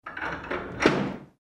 Cerrar el portón de madera de un palacio